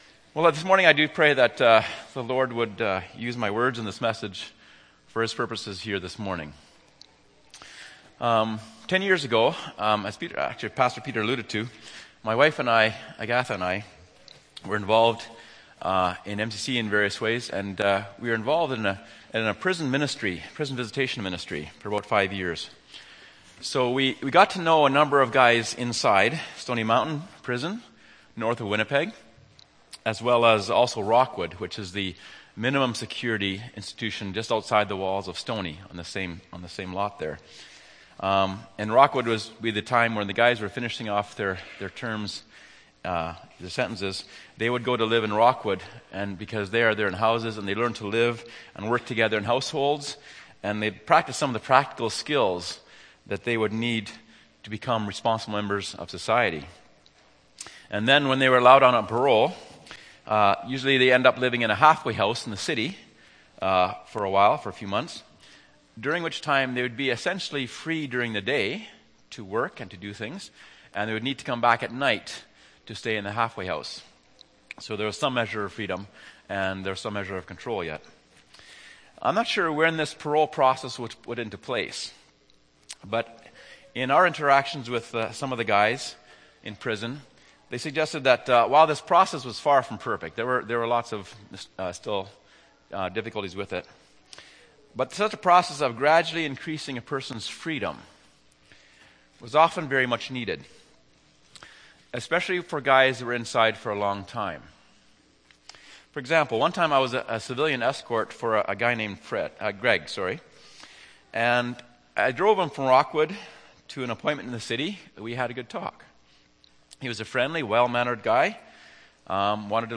Nov. 24, 2013 – Sermon